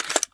rpk47_bipod_open.wav